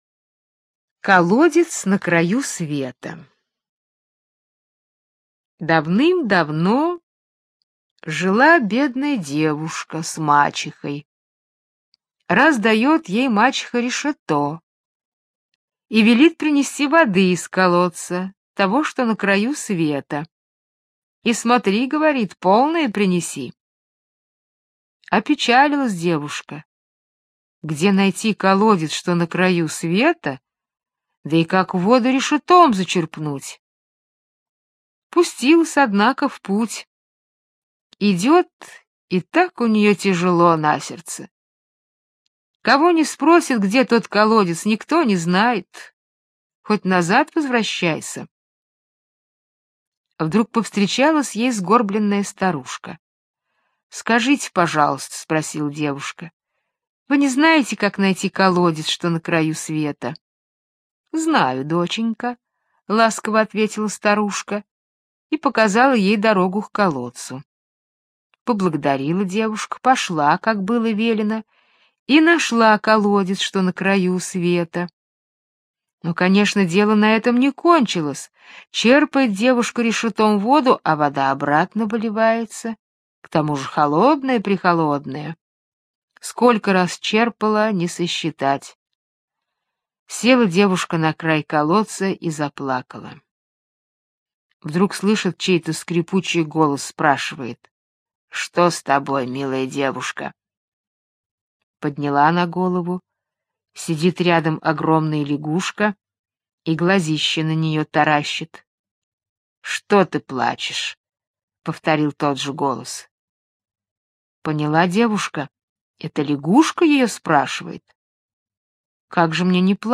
Колодец на краю света - британская аудиосказка. Про добрую девушку, которая по приказу мачехи ходила на край света с решетом за водой.